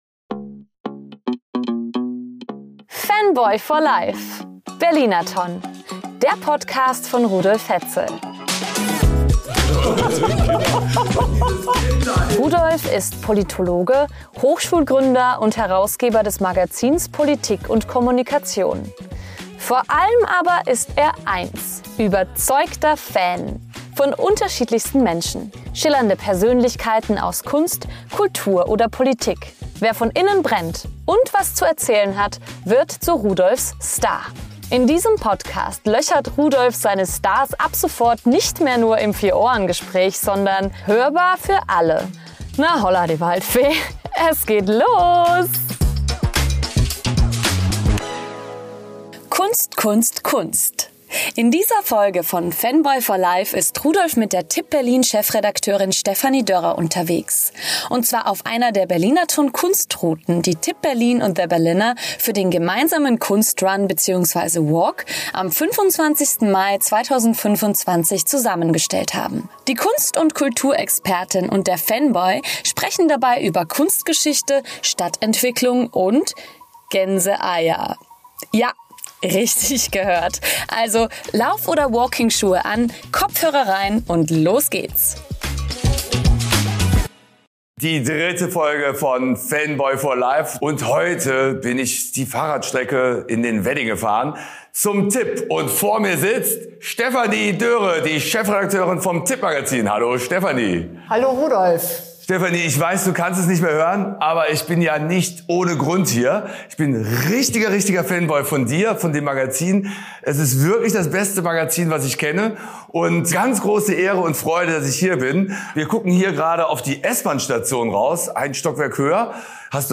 Dabei wird über Kunstgeschichte, Stadtentwicklung und persönliche Erinnerungen, die mit den Orten verknüpft sind, gequatscht - und, es werden Gänseeier vernascht.